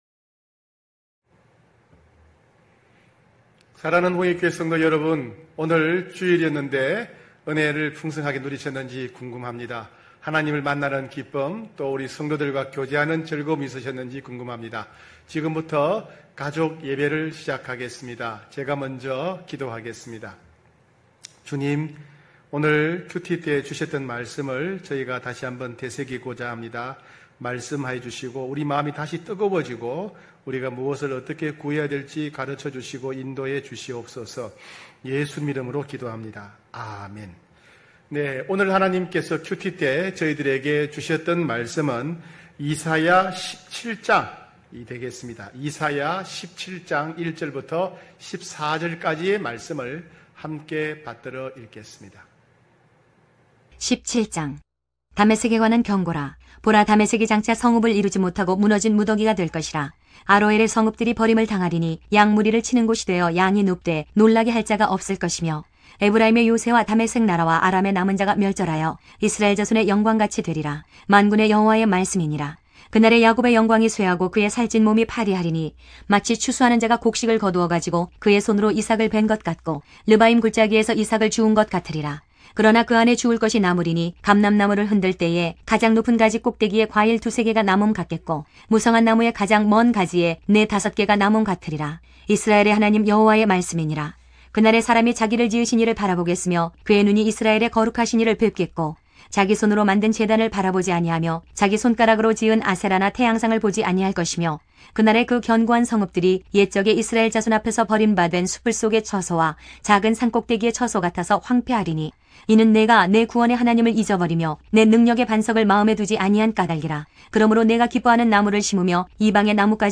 9시홍익가족예배(8월2일).mp3